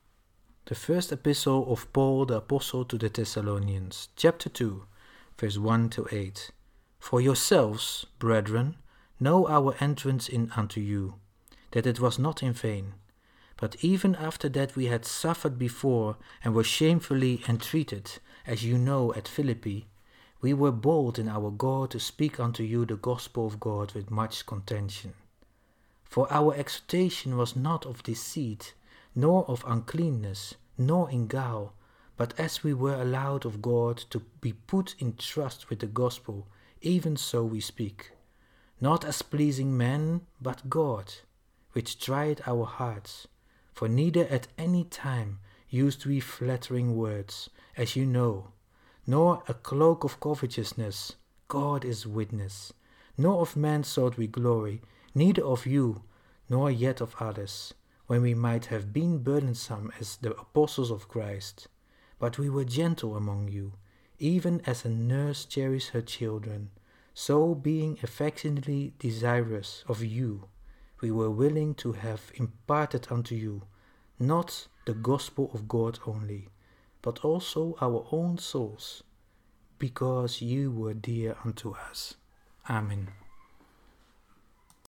topic: Bible reading
Bible reading. (KJV)